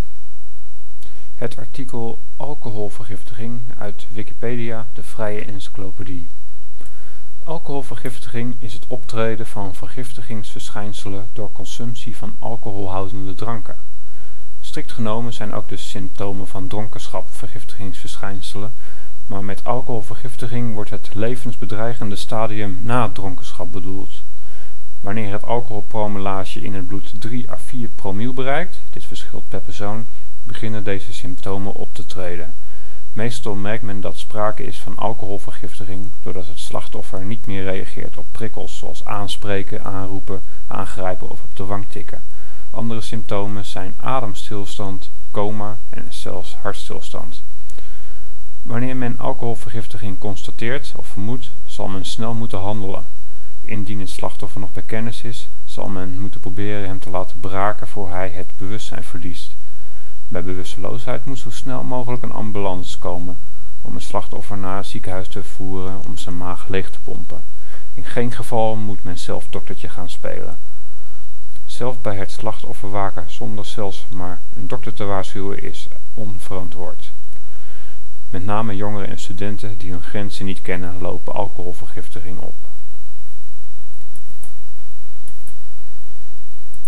zelf ingesproken
Nl-Alcoholvergiftiging-article.ogg